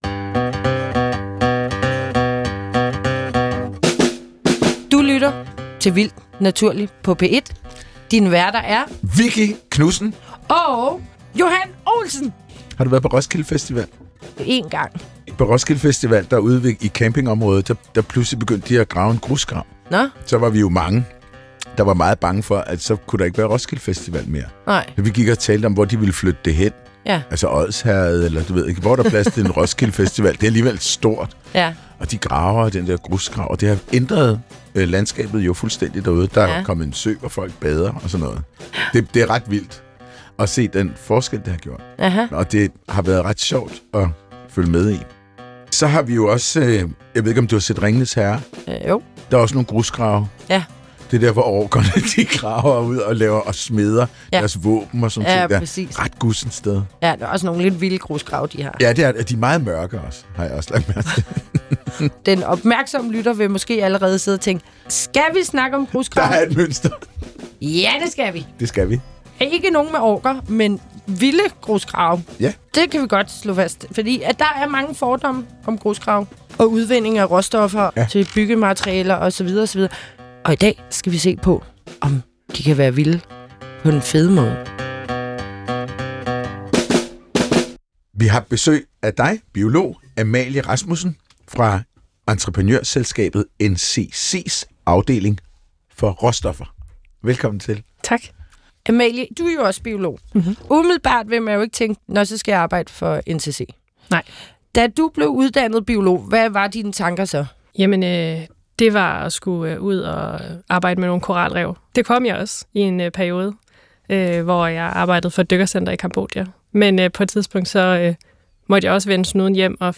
Værter: Biologerne